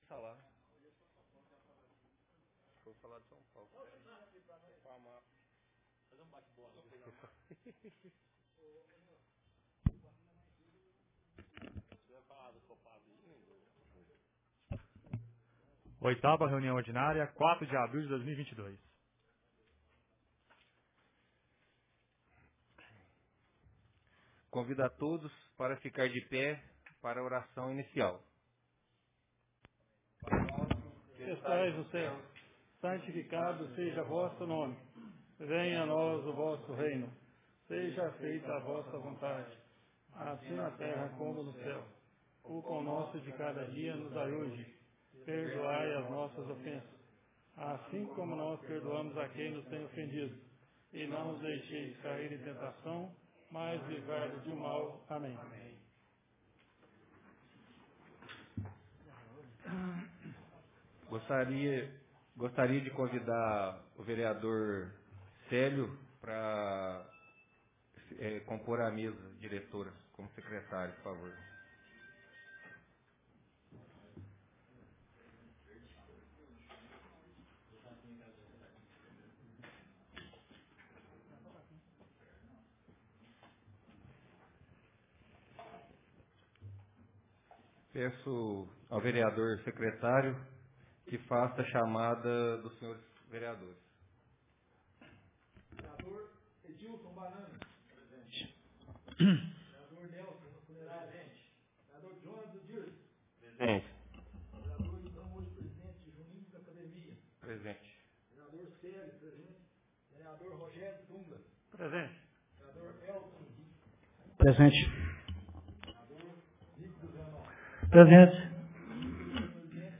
Ata da 8ª Reunião Ordinária de 2022